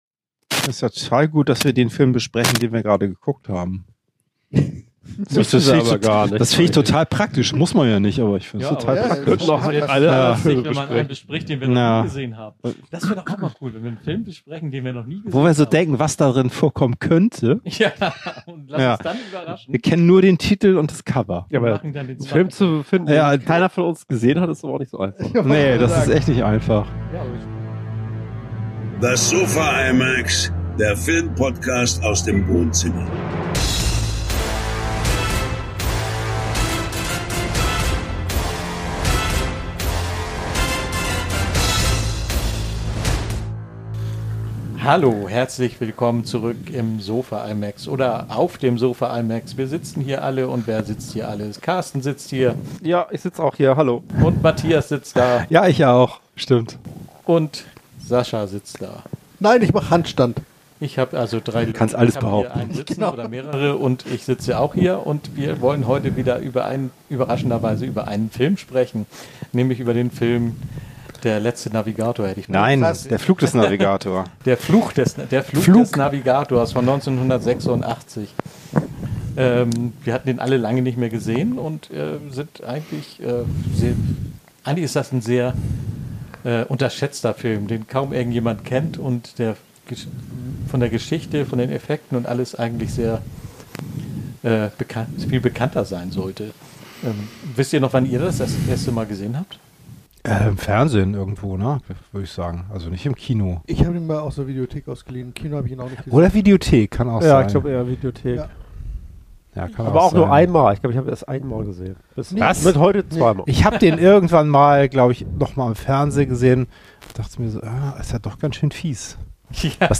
Der Film Podcast aus dem Wohnzimmer. Freunde besprechen Filme im Wohnzimmer.